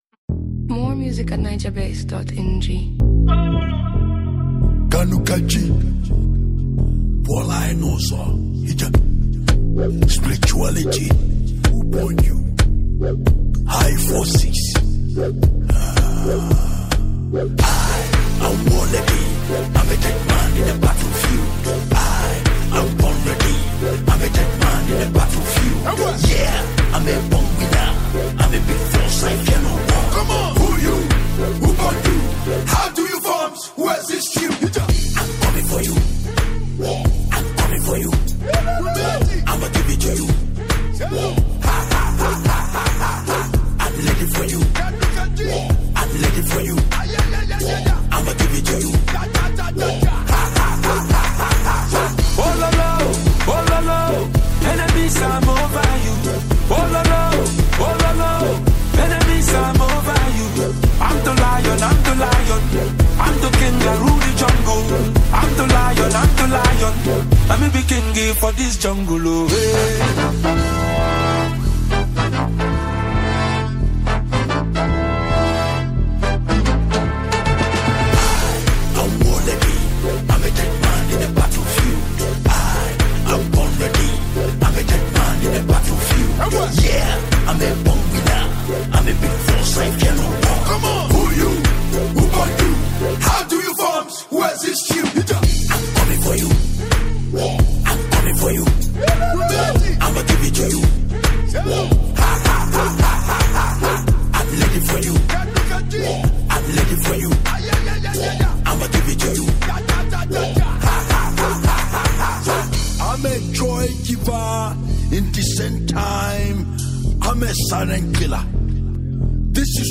high-energy Afro-music anthem